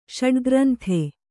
♪ ṣaḍgranthe